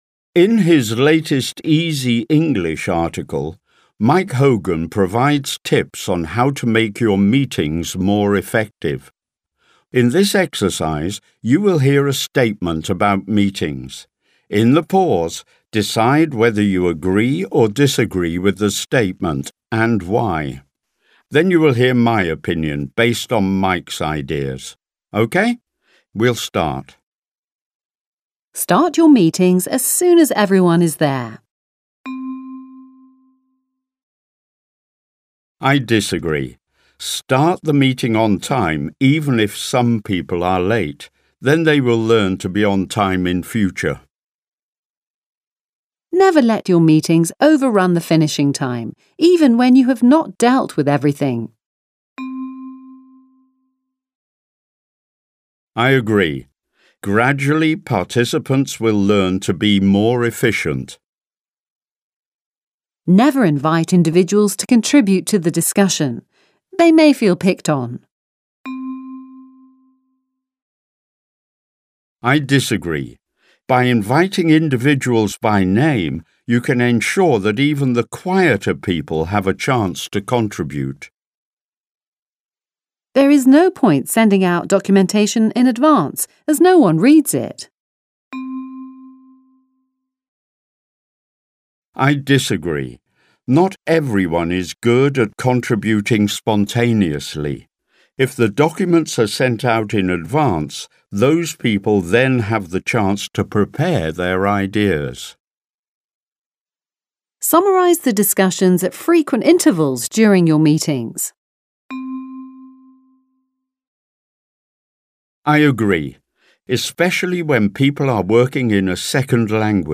Audio-Übung